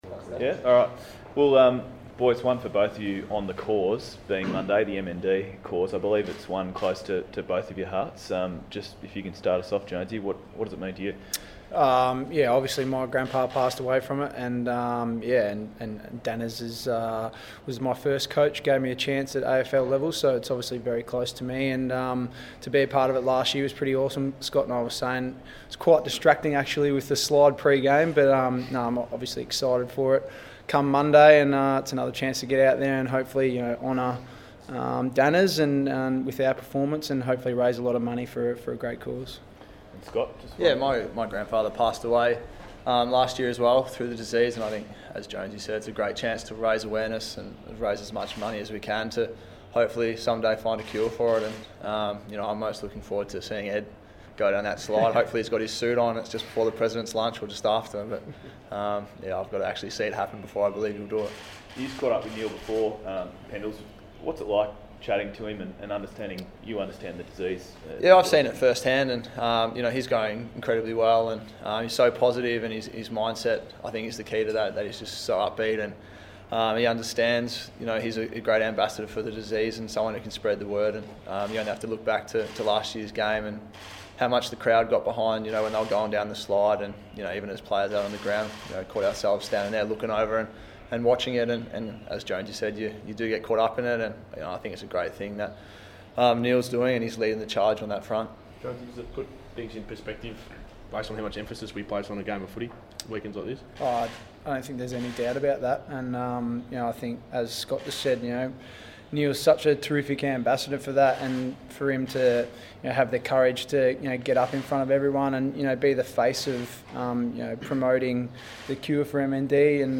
Press Conference: Scott Pendlebury and Nathan Jones
Join captains Scott Pendlebury and Nathan Jones as they address the media ahead of the Queen's Birthday blockbuster at the MCG.